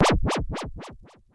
模拟打击乐 " FX摇摆器1
描述：我用我的Korg Monotribe录制这些声音。
Tag: 模拟 打击乐器 摆动 FX monotribe